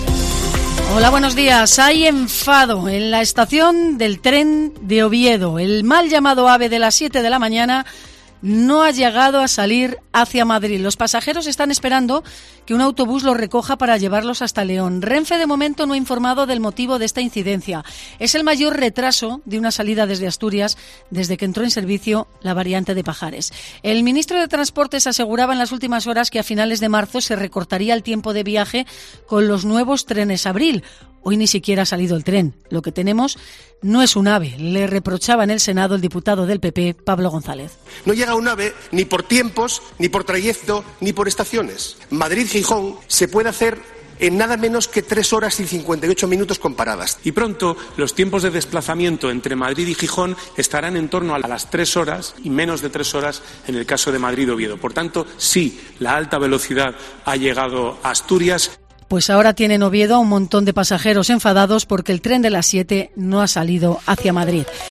Así hemos contado en COPE la avería del mal llamado AVE entre Oviedo y Madrid